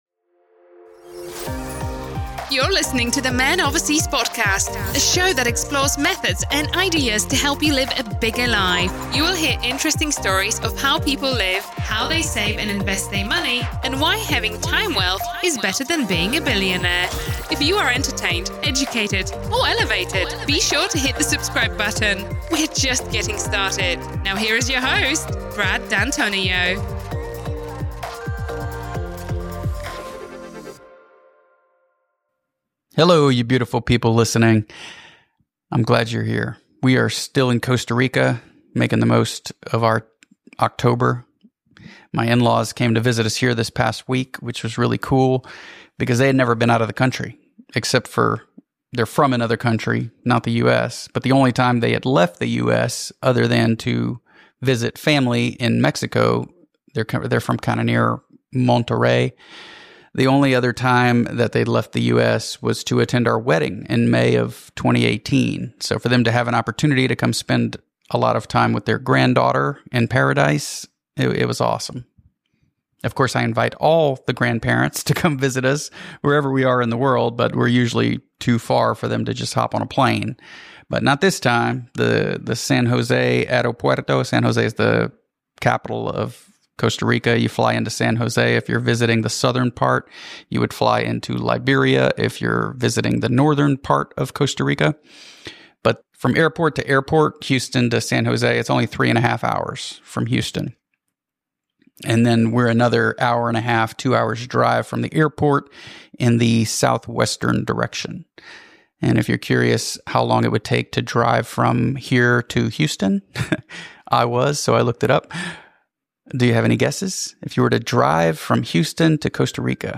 Solo again from Costa Rica.